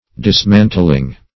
dismantling.mp3